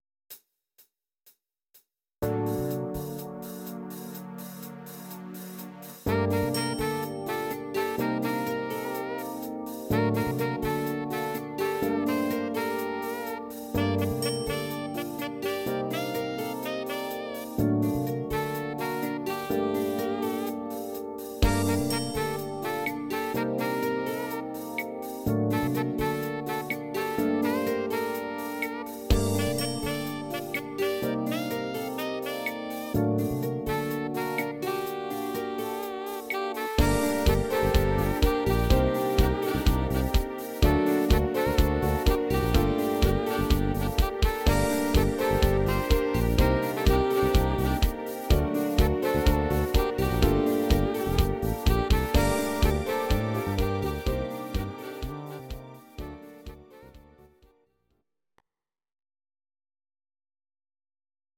Audio Recordings based on Midi-files
Pop, 1990s